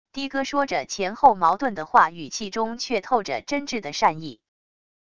的哥说着前后矛盾的话语气中却透着真挚的善意wav音频